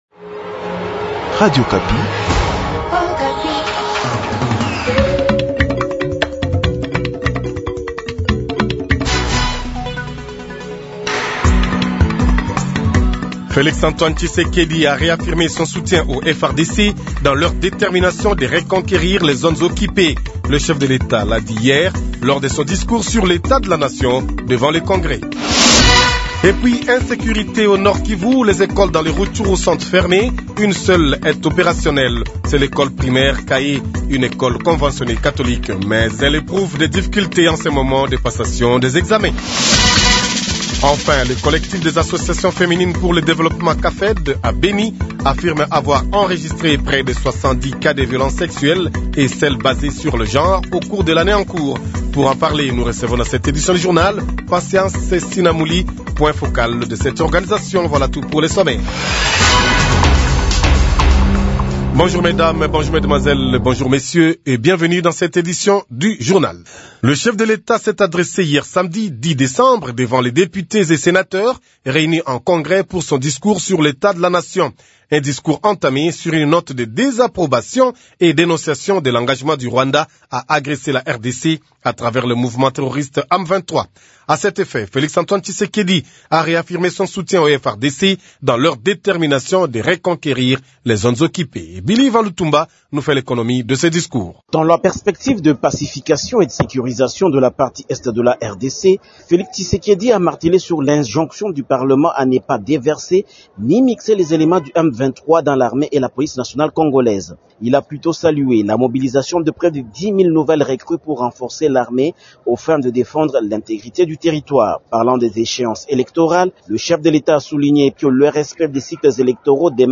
Journal Matin
KIN : Extrait discours de Fatshi sur l’agression